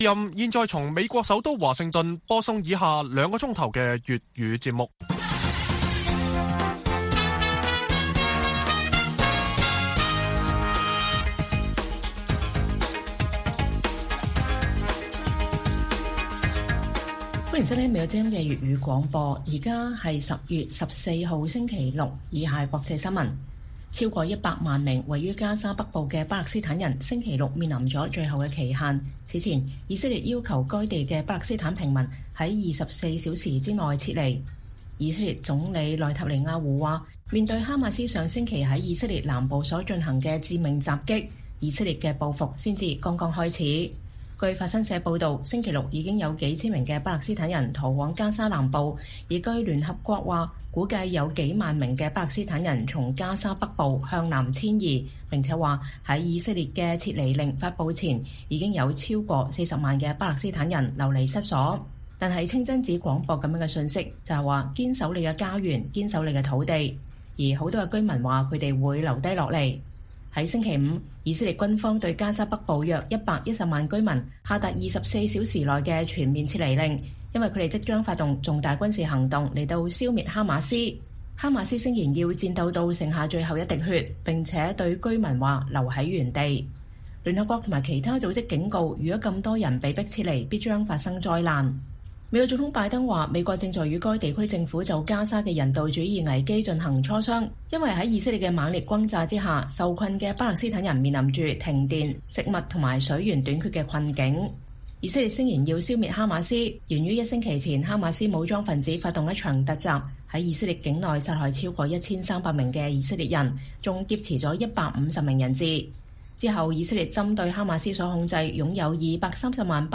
粵語新聞 晚上9-10點：以色列發出撤離警告後 聯合國估計有數萬巴勒斯坦人逃離加沙北部